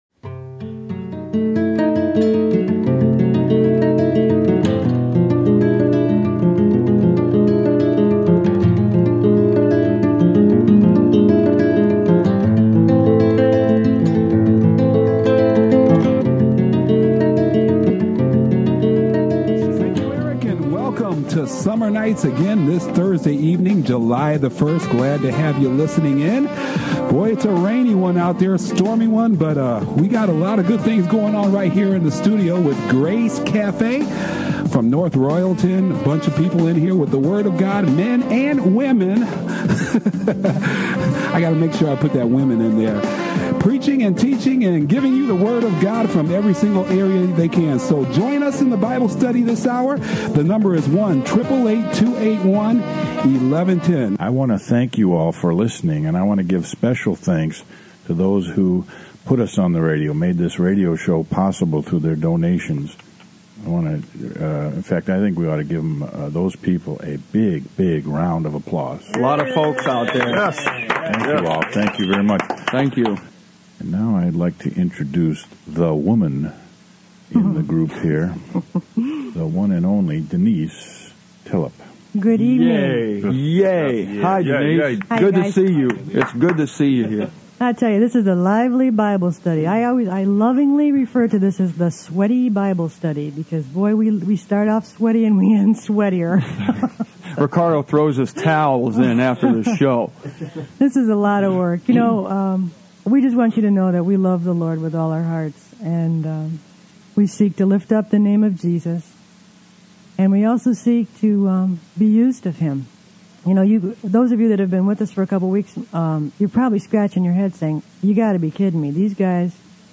While last week's show was a hard-hitting Scripture-based exposition, this show is a personal, heartfelt inquiry as to why no one seems to care about the truth. Here is the sad reality of Christianity: like the Pharisees of old, this religion loves tradition more than truth.